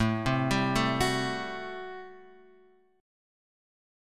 A Minor 13th